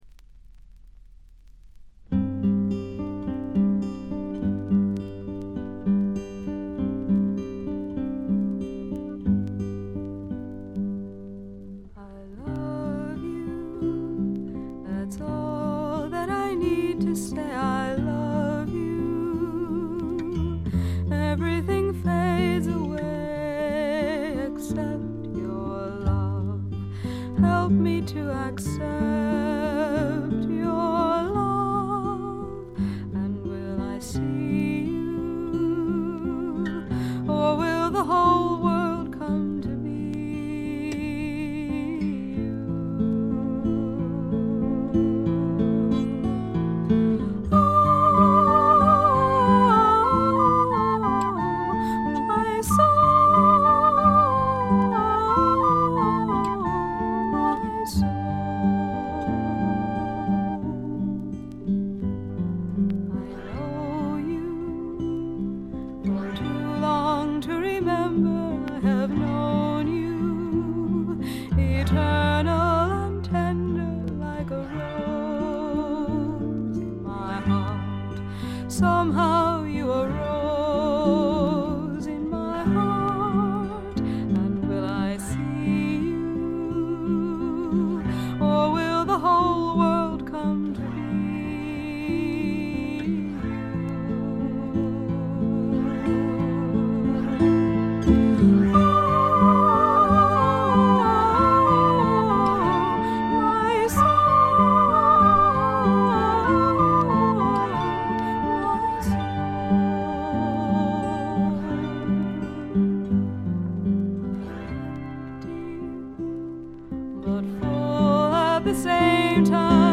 ホーム > レコード：米国 女性SSW / フォーク
弾き語りを中心にごくシンプルなバックが付く音作り、トラッドのアカペラも最高です。
Vocals, Guitar, Autoharp, Recorder